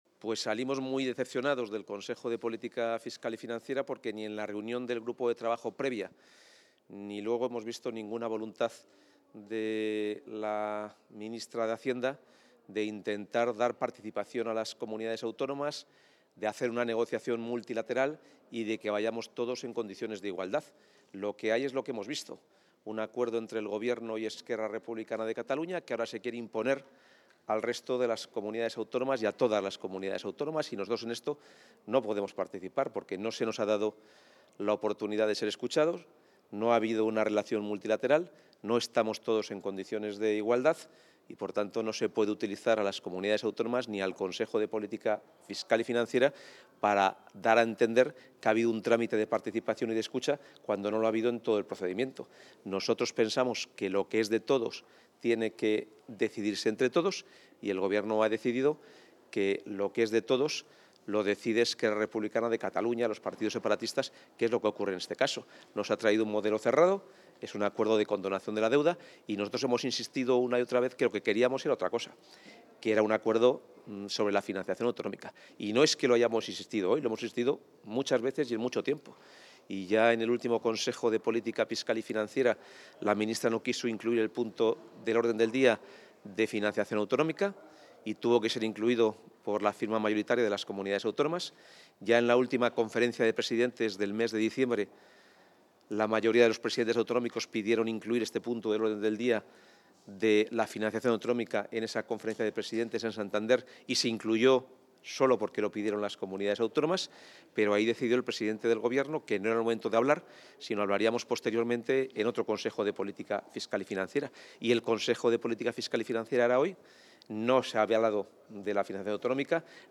Valoración del consejero de Economía y Hacienda del Consejo de Política Fiscal y Financiera